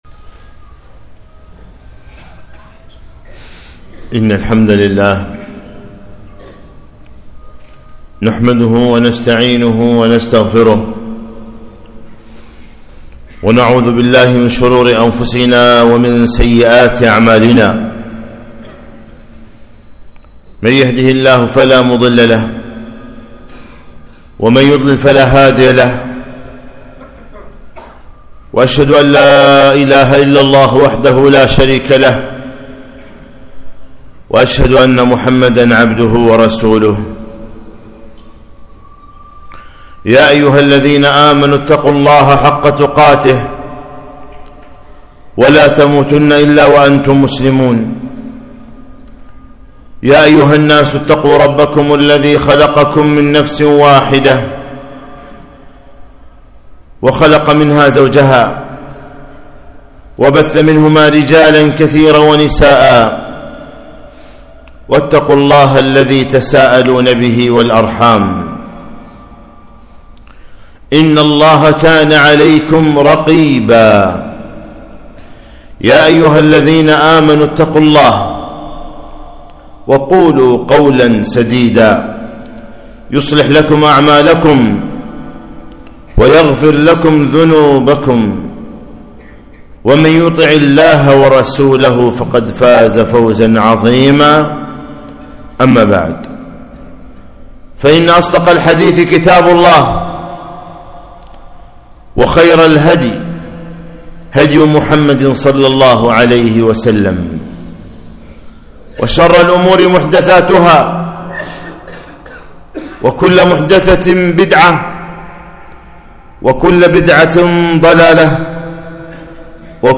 خطبة - السنة سفينة نوح